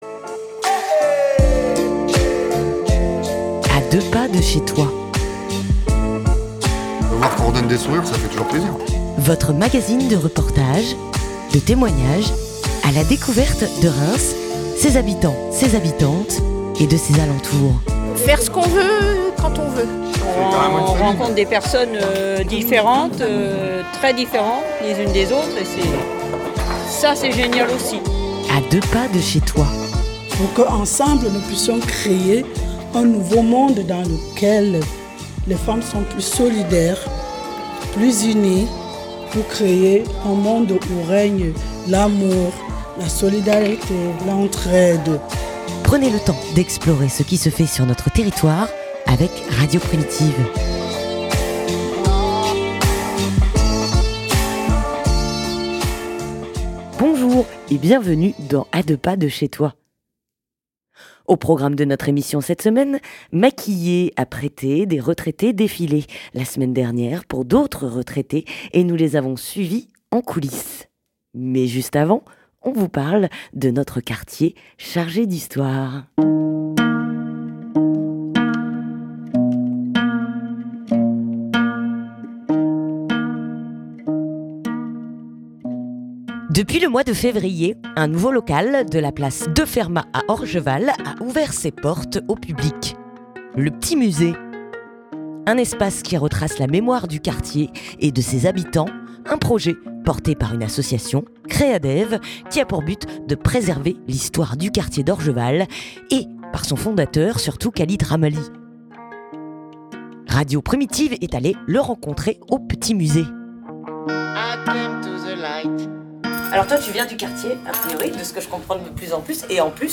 Radio Primitive vous emmène en coulisse.